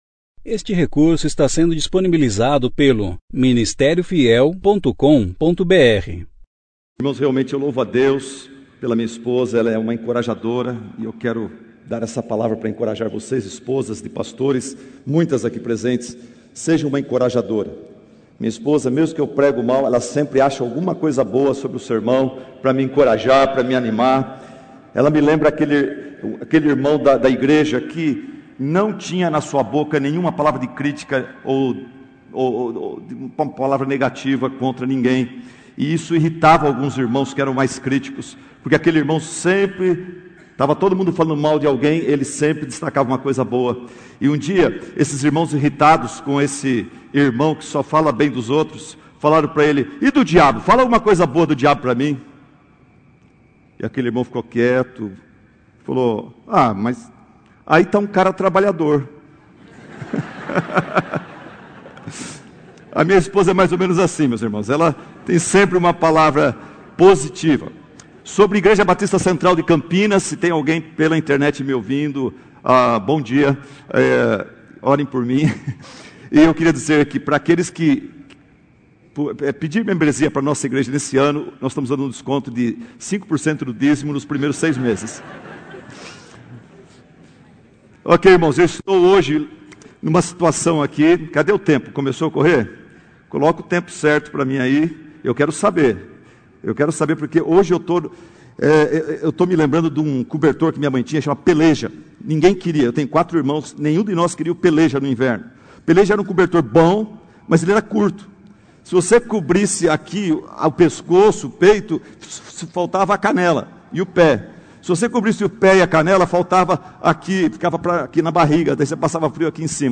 Conferência: 33ª Conferência Fiel para Pastores e Líderes - Brasil Tema: Protestantes Ano: 2017 Mens